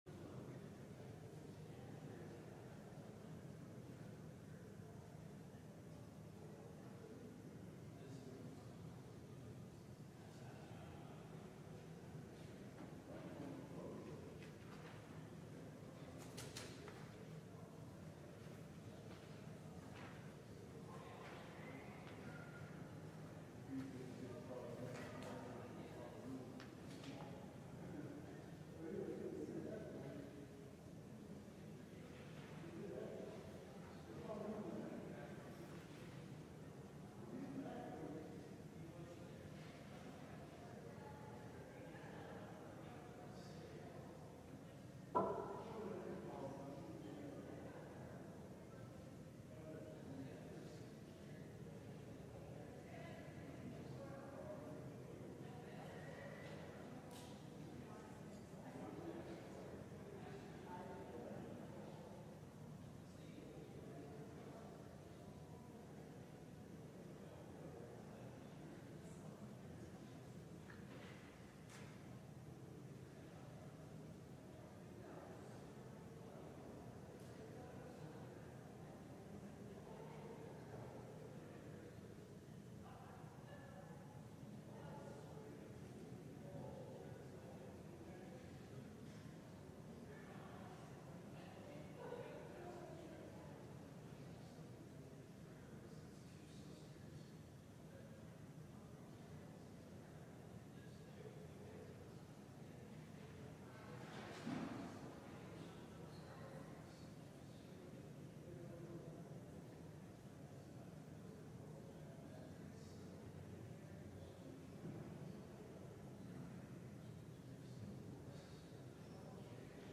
LIVE Evening Worship Service - The Dawning Day